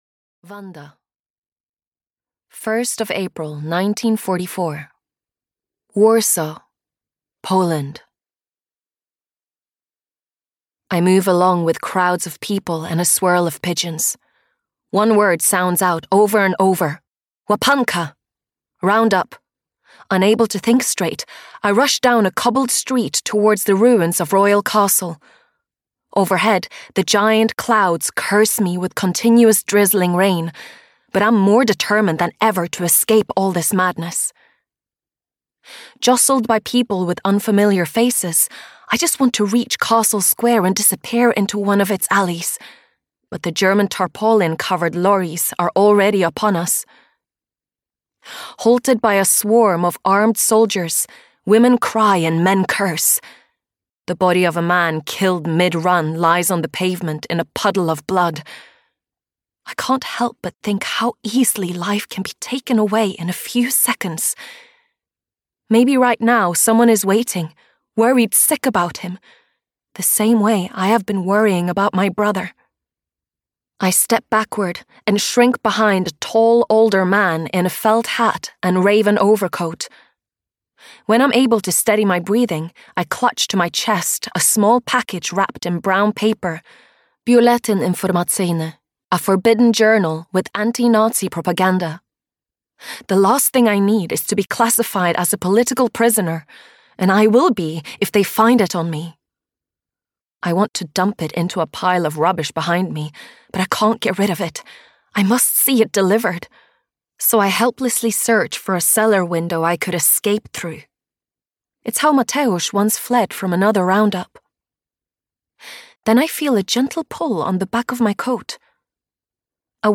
Her Secret Resistance (EN) audiokniha
Ukázka z knihy